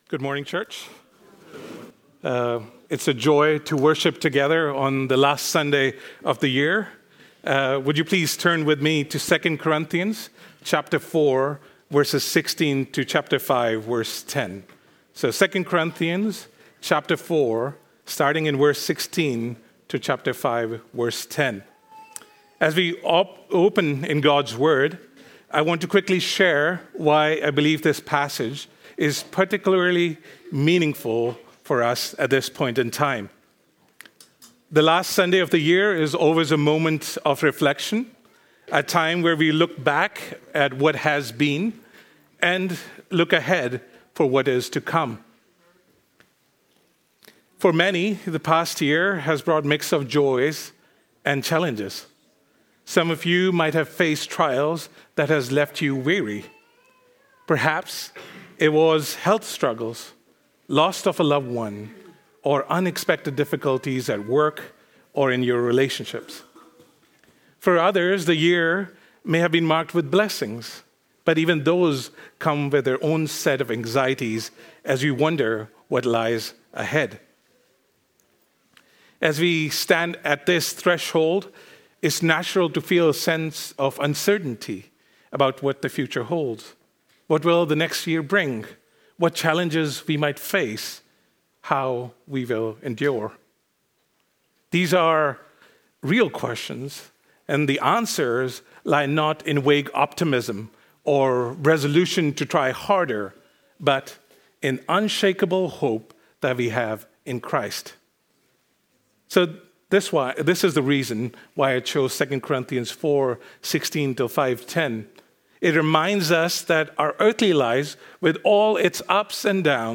Series Sermons